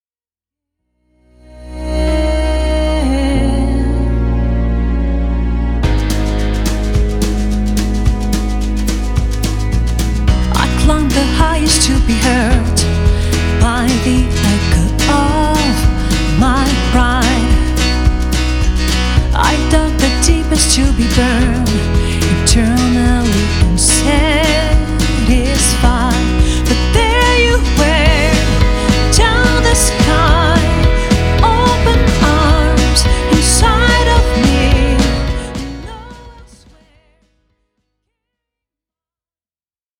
Dans un style pop saupoudré de musique du monde